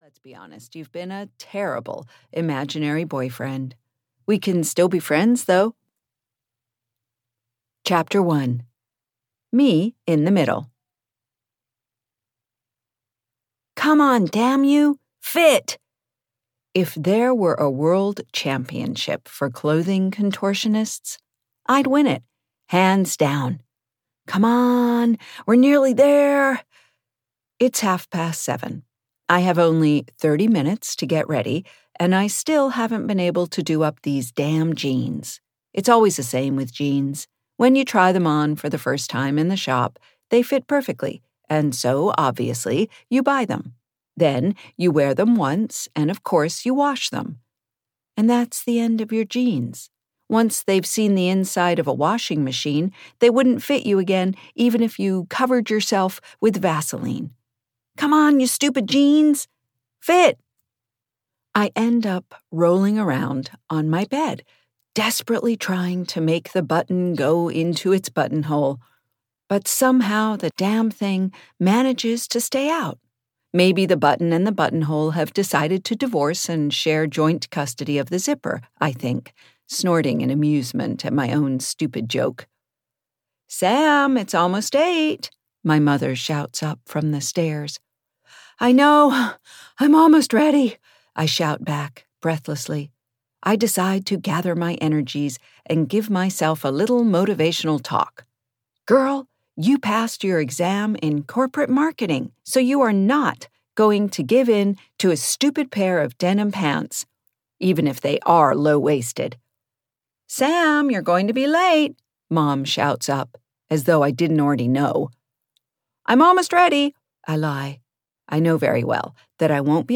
Who's that Girl? (EN) audiokniha
Ukázka z knihy